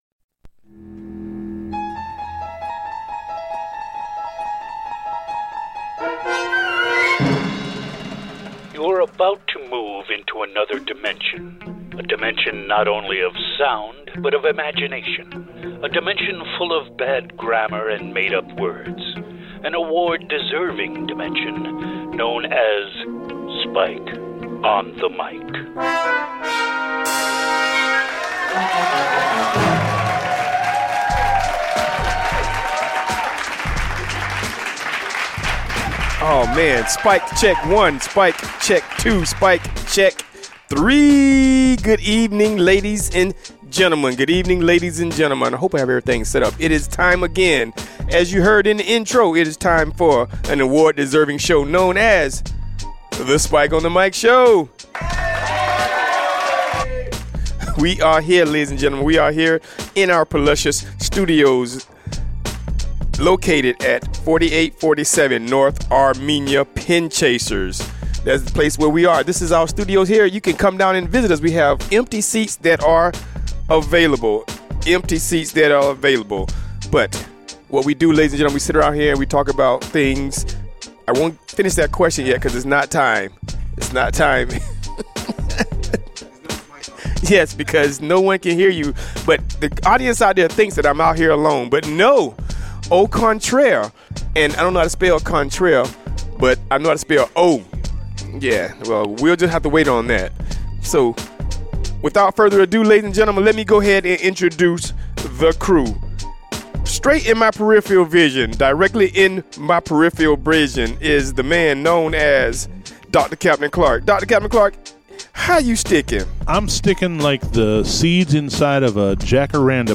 Live From Tampa!